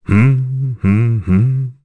Bernheim-Vox_Hum_kr.wav